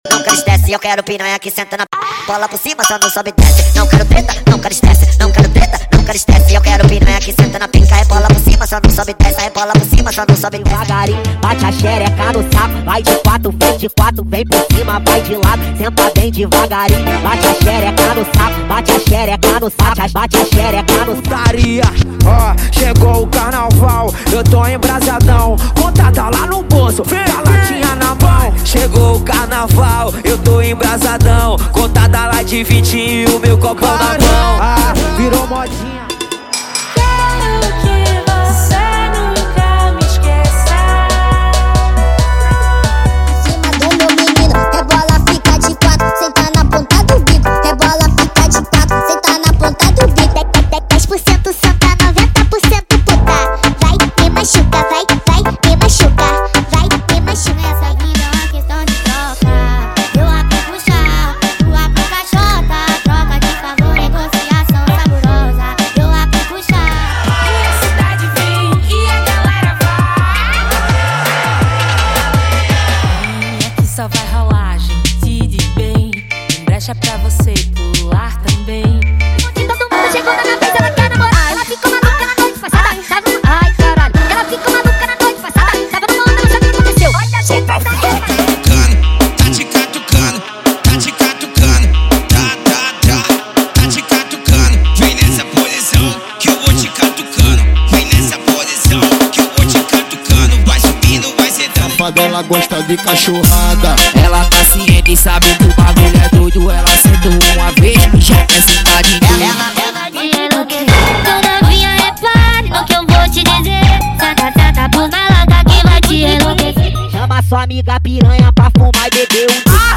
Os Melhores Brega Funk do momento estão aqui!!!
• Sem Vinhetas